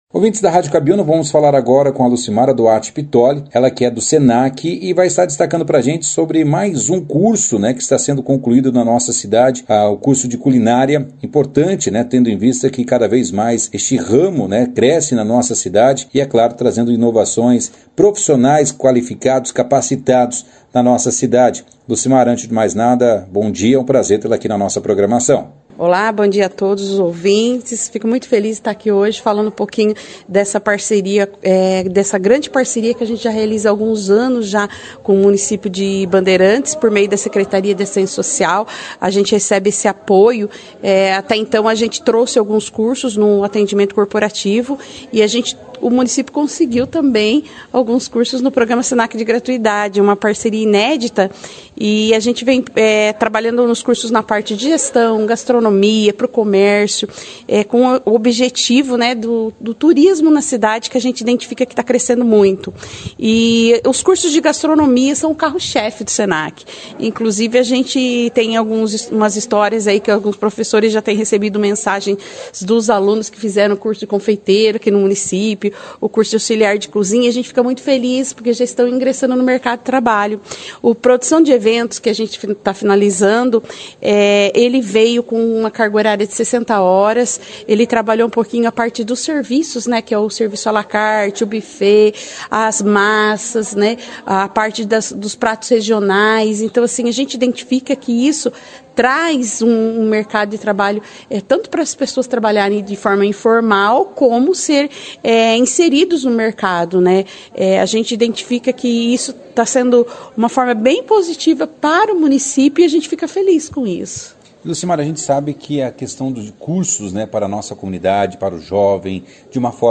Final do curso de culinária promovido pela prefeitura de Bandeirantes em parceria com o Senac Paraná foi realizada na manhã da última terça-feira, 30 de maio. O evento foi destaque na primeira edição desta quarta-feira, 31 de maio, do jornal Operação Cidade, ressaltando a importância dessa conquista para os participantes.